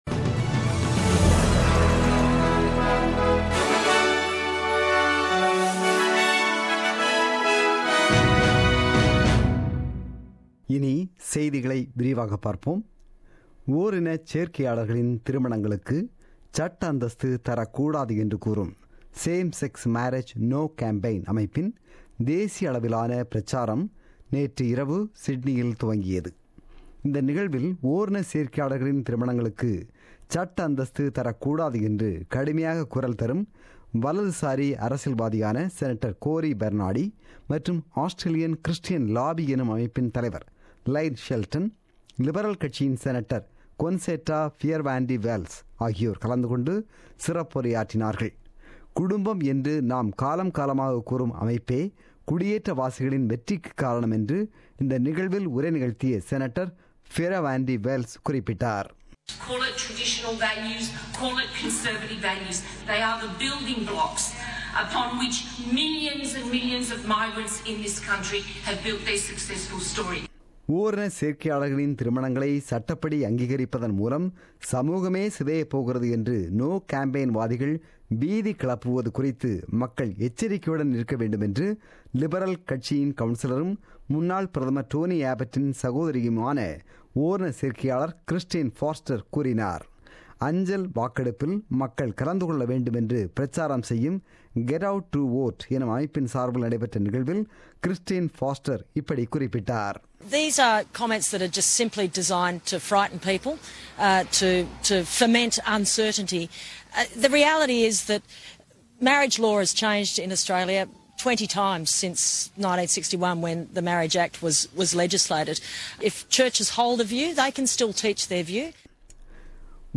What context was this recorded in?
The news bulletin broadcasted on 17 September 2017 at 8pm.